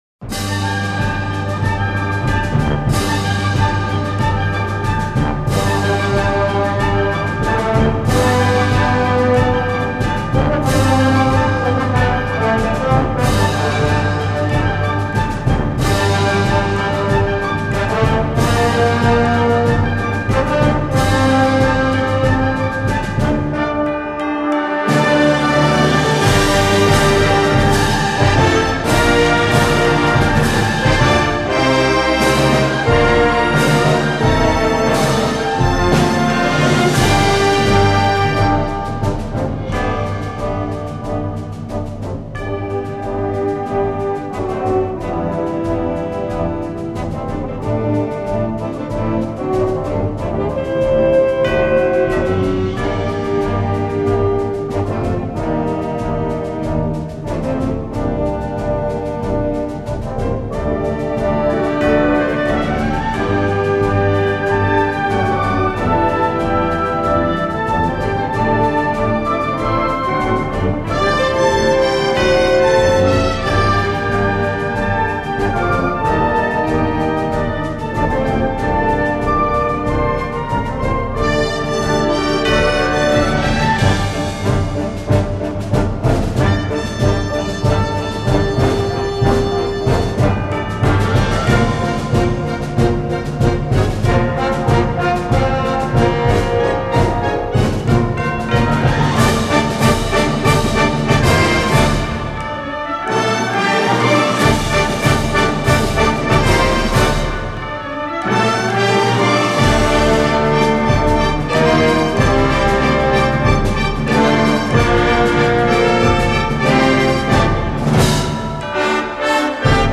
Genre: Marches chrétiennes